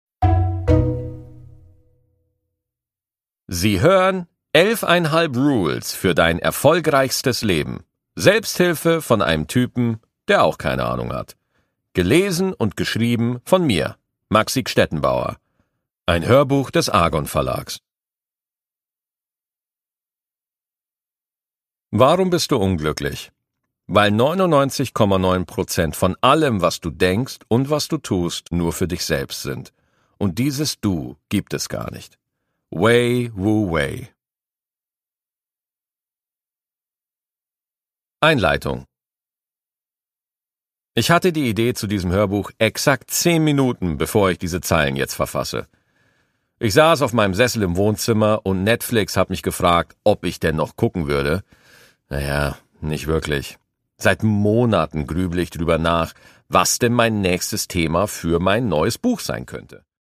Produkttyp: Hörbuch-Download
Gelesen von: Maxi Gstettenbauer